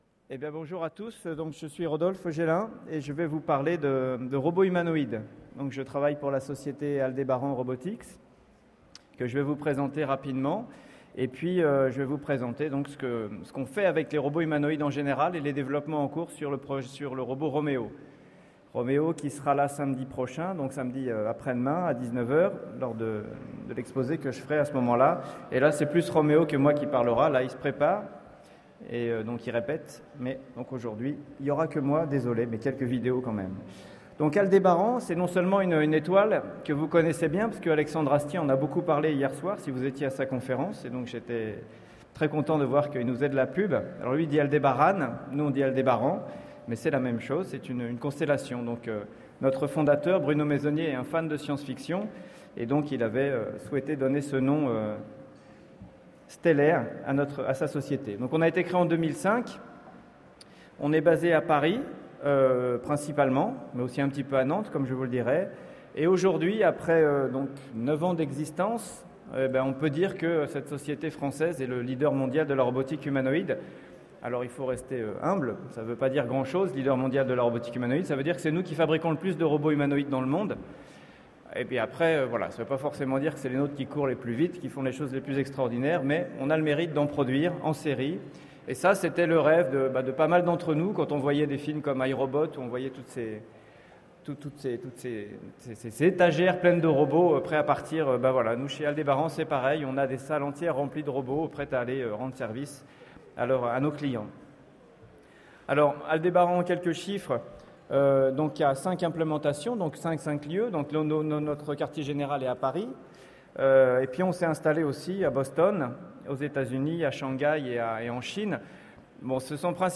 Mots-clés Robot Conférence Partager cet article